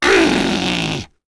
Lakrak-Vox-Deny4.wav